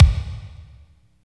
28.08 KICK.wav